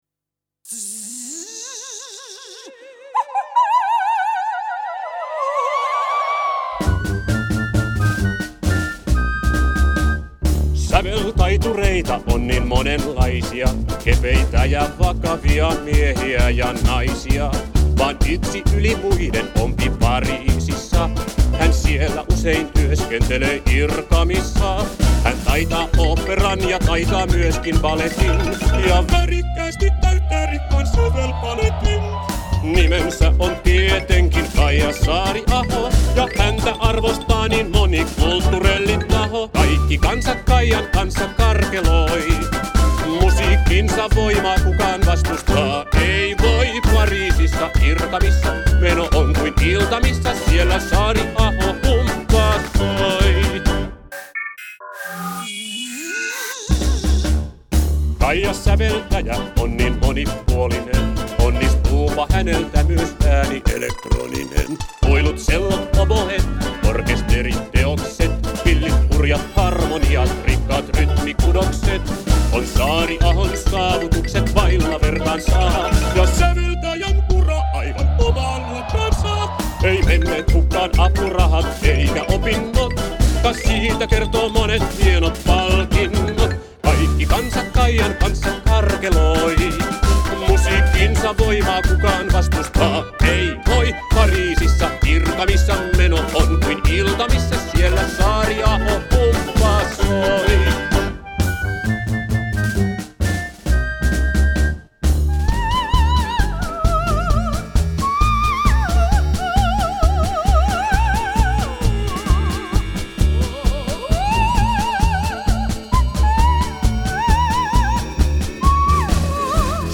Saariaho-humppa.mp3